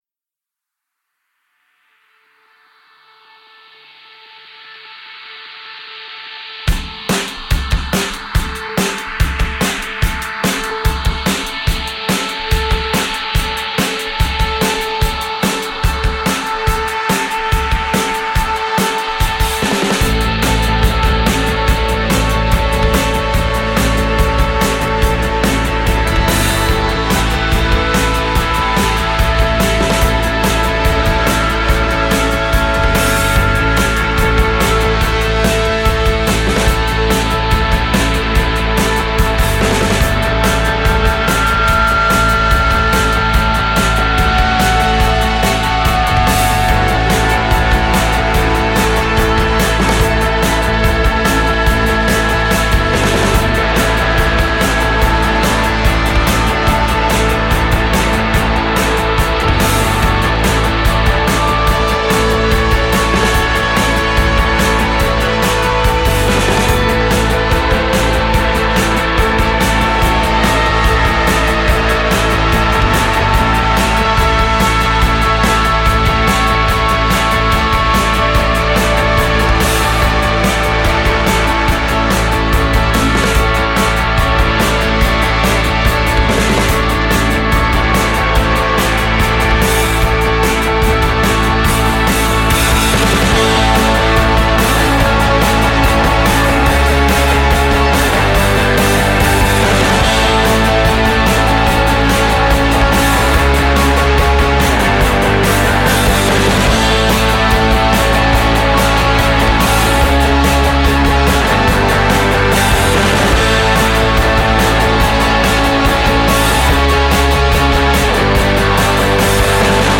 alt-rockers